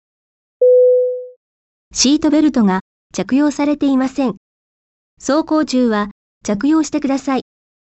音声案内　改め[音声警告システム]
シートベルト未着用警告